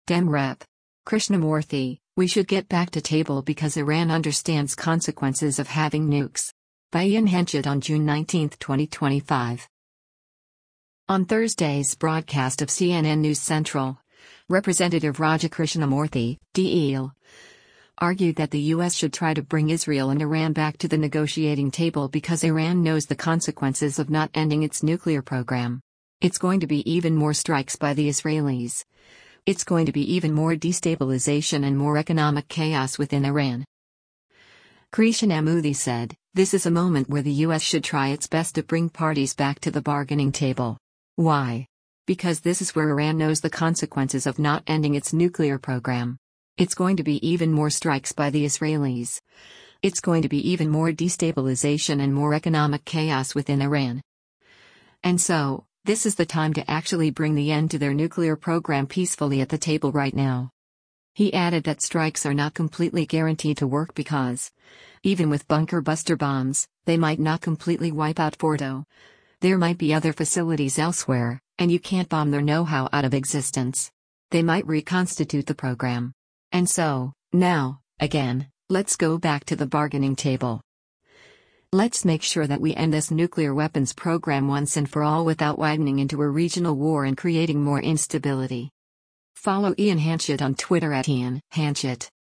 On Thursday’s broadcast of “CNN News Central,” Rep. Raja Krishnamoorthi (D-IL) argued that the U.S. should try to bring Israel and Iran back to the negotiating table because “Iran knows the consequences of not ending its nuclear program. It’s going to be even more strikes by the Israelis, it’s going to be even more destabilization and more economic chaos within Iran.”